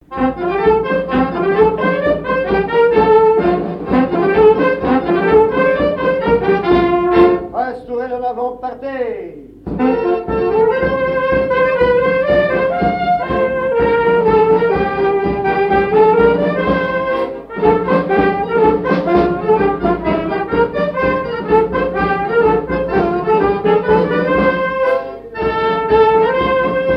danse : quadrille : pastourelle
Pièce musicale inédite